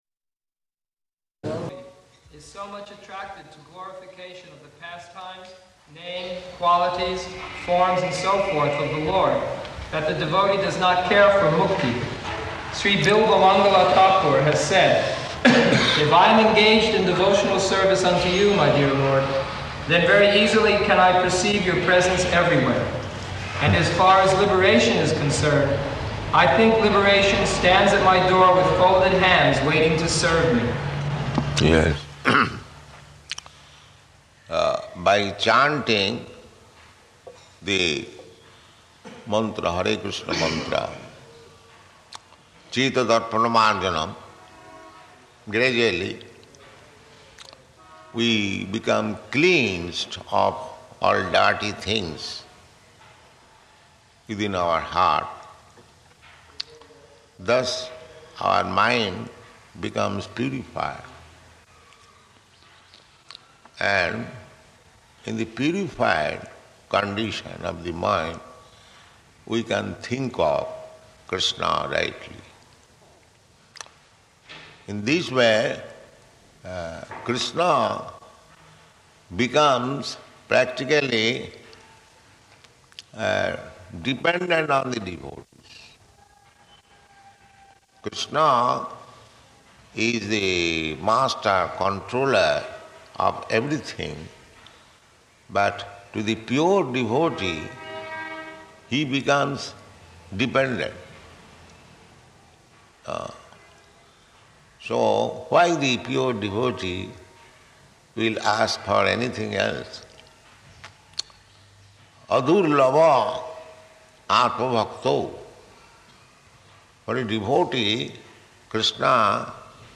Location: Calcutta